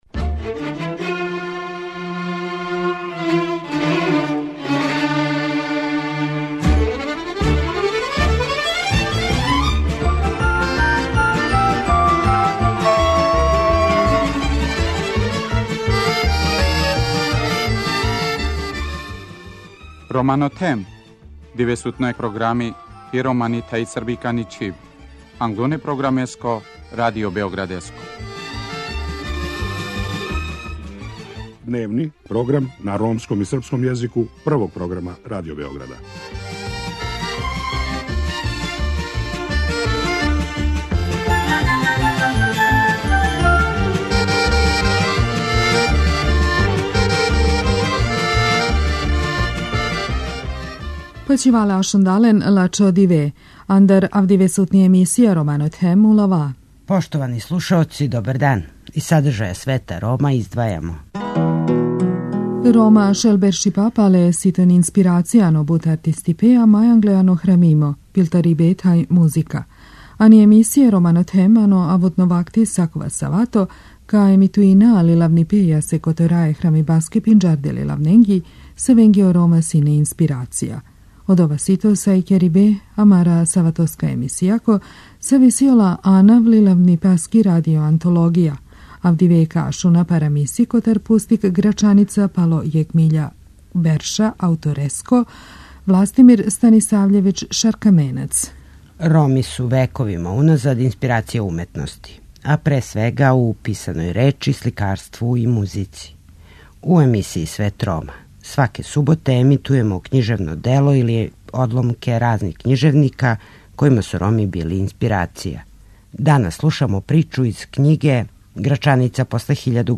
Данас слушамо причу из књиге Грачаница после 1000 година аутора Властимира Станисављевића - Шаркаменца.